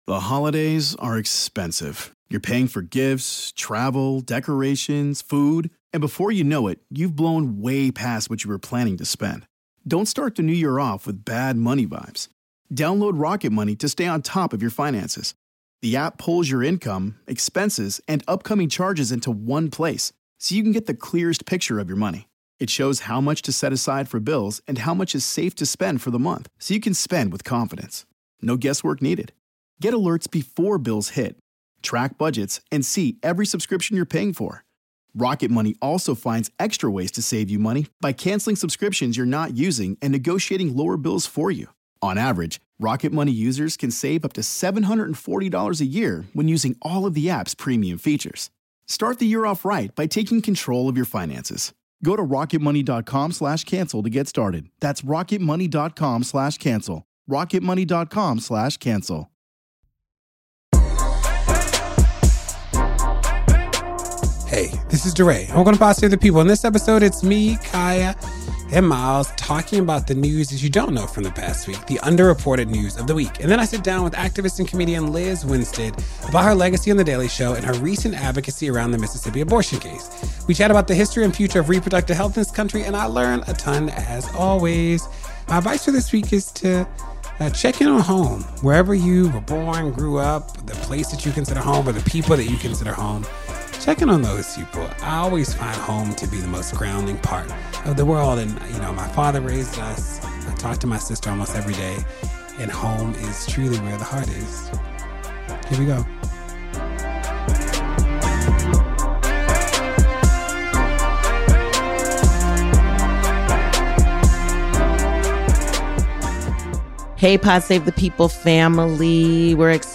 DeRay interviews activist and comedian Lizz Winstead about her legacy on the Daily Show and her recent advocacy around the Mississippi abortion case.